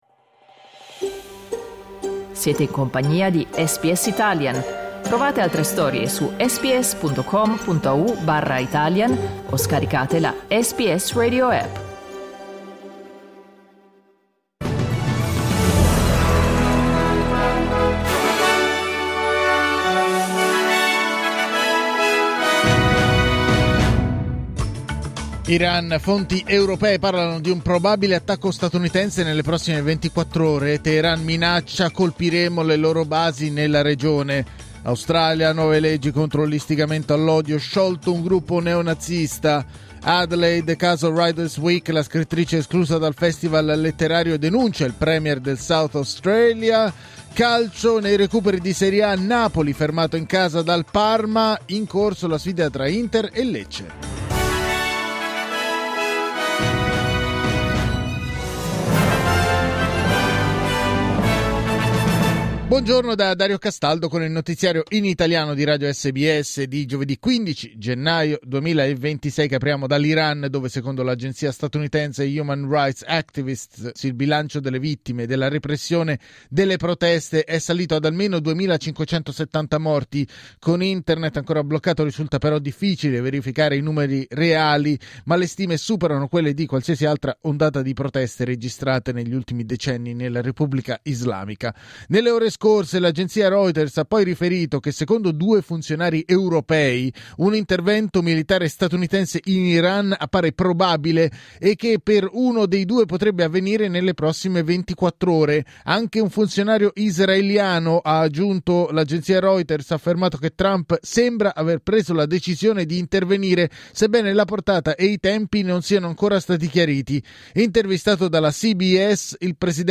Giornale radio giovedì 15 gennaio 2026
Il notiziario di SBS in italiano.